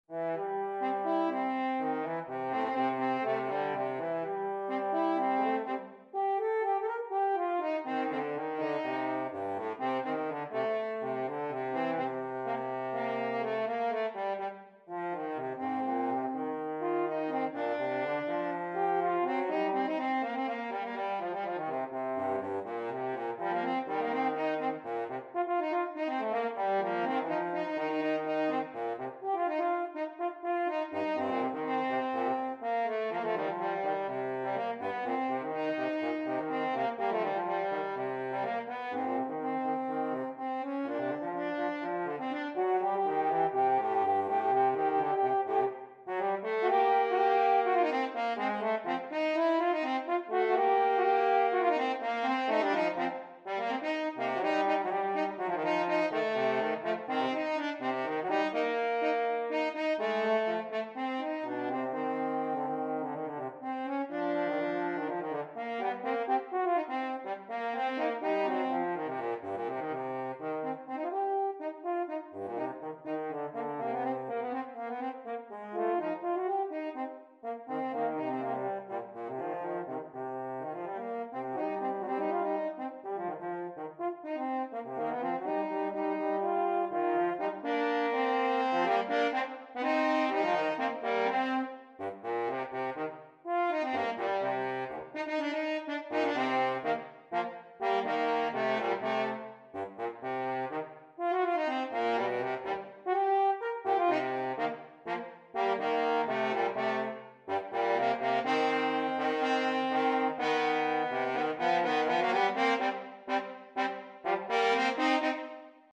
Gattung: Für 2 Hörner
Besetzung: Instrumentalnoten für Horn